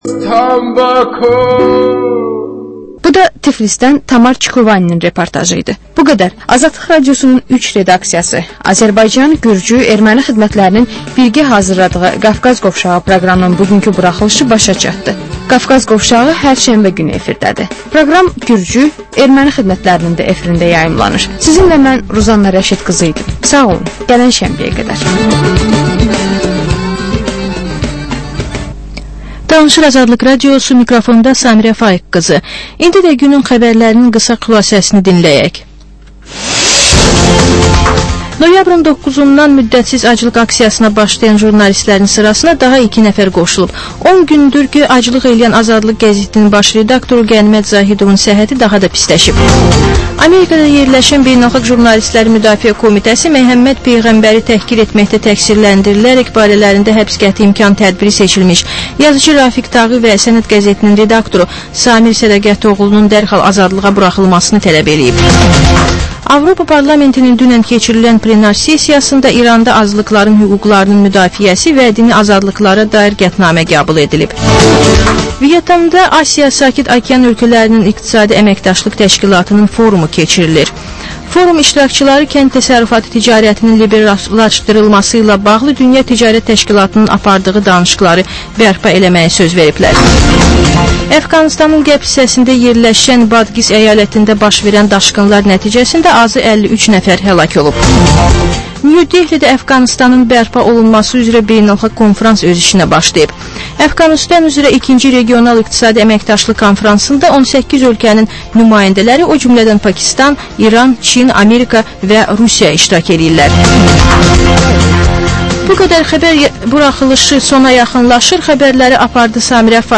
Jurnalistlər və həftənin xəbər adamıyla aktual mövzunun müzakirəsi